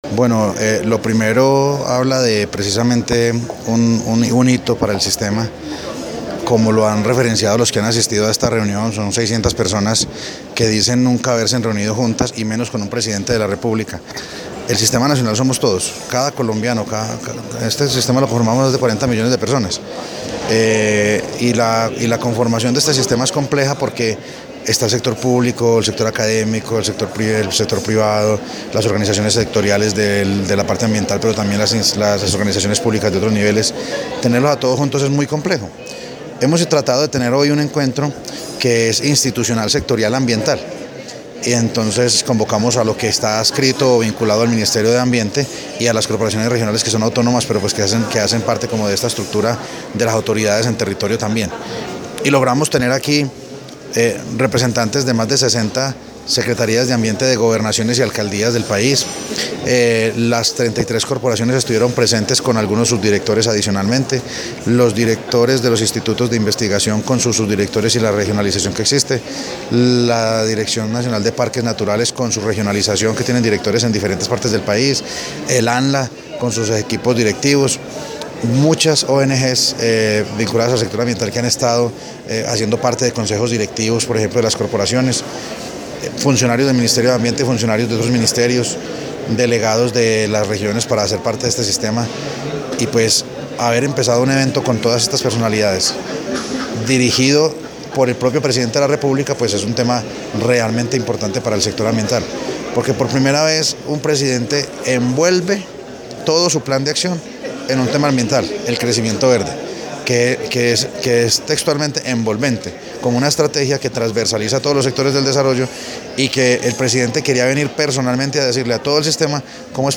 Declaraciones de Luis Alberto Giraldo, Director de Ordenamiento Ambiental, Territorial y Coordinación del SINA audio